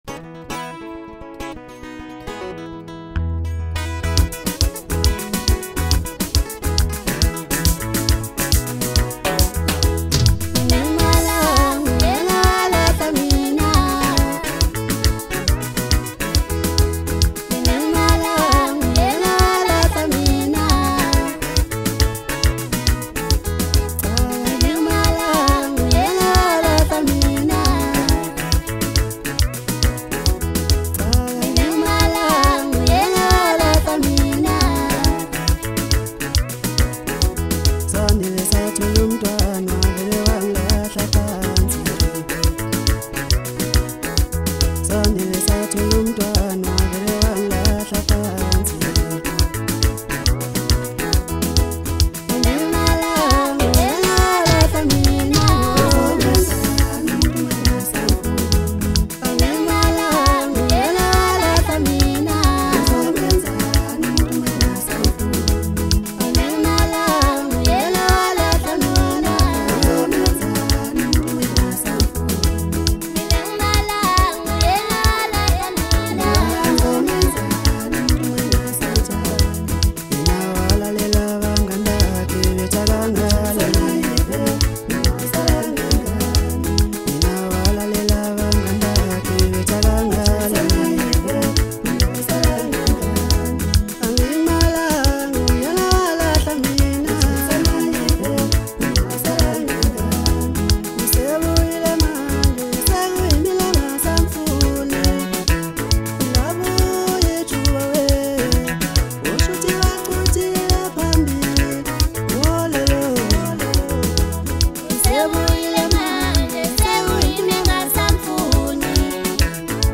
Home » Maskandi